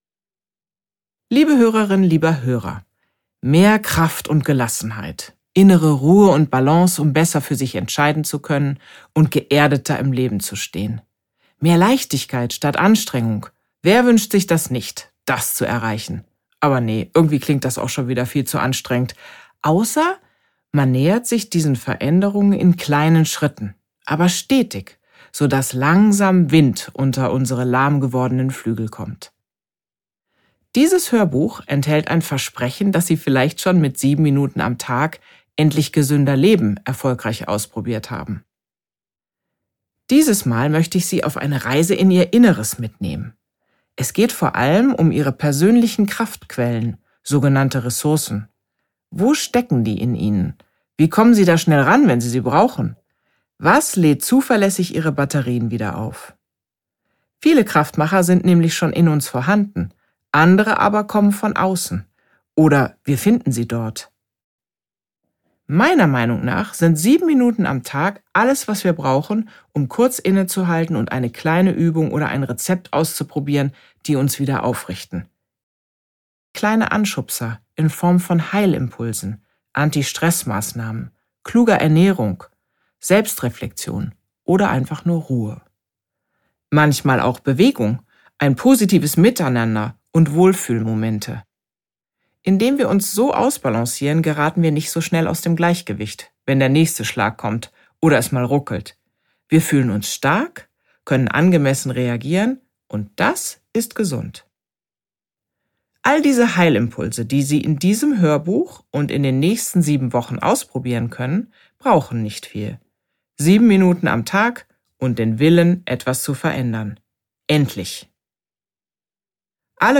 7 Minuten am Tag endlich kraftvoll und gelassen Was die Seele stark macht! | Eine stabile Psyche in nur 7 Minuten am Tag – das erprobte, ganzheitliche Erfolgsprogramm Franziska Rubin (Autor) Franziska Rubin (Sprecher) Audio-CD 2023 | 1.